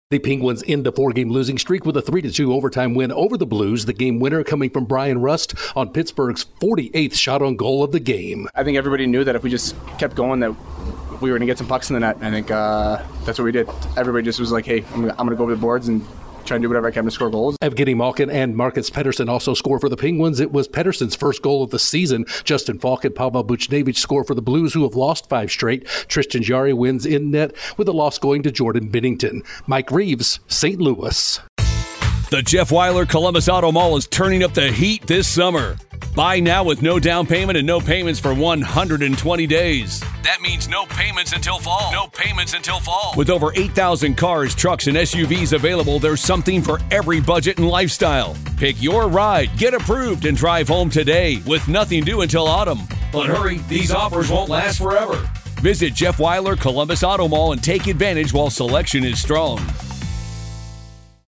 The Penguins finally get a victory by topping the slumping Blues. Correspondent